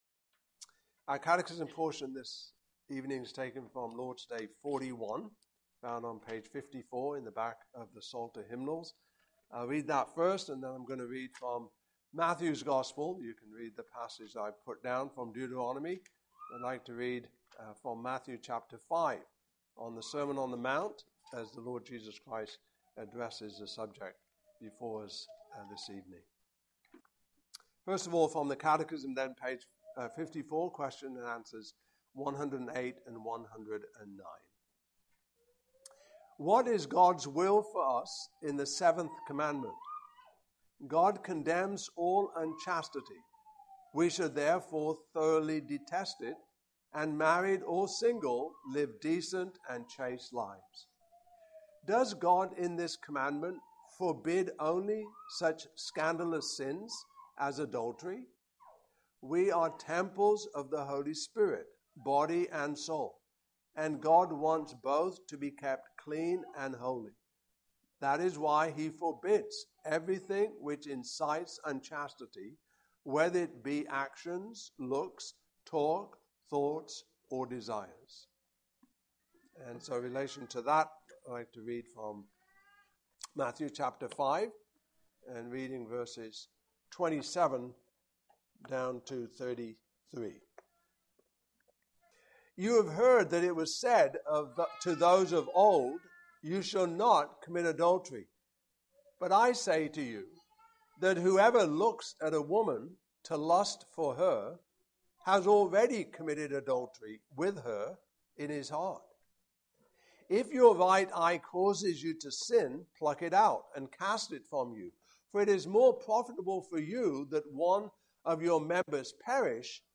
Passage: Deuteronomy 22:13.30 Service Type: Evening Service